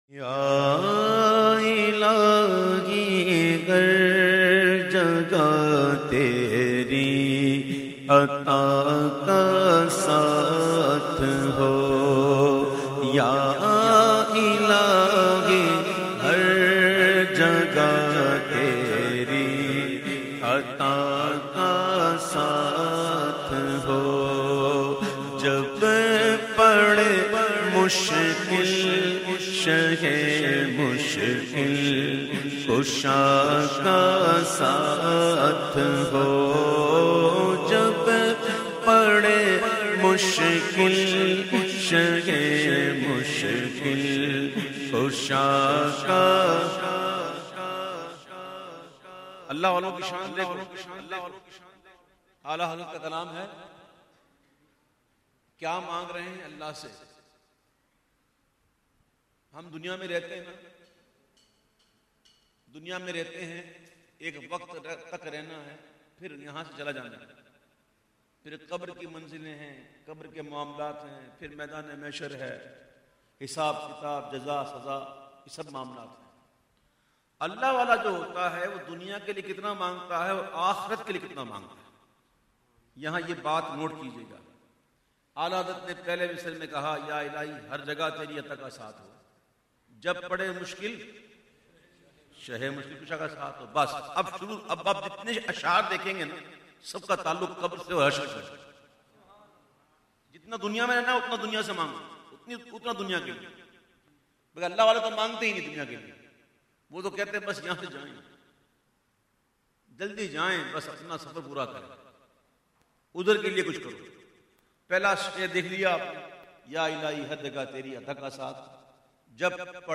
The Naat Sharif Ya Ilahi Har Jaga recited by famous Naat Khawan of Pakistan Owaise Raza Qadri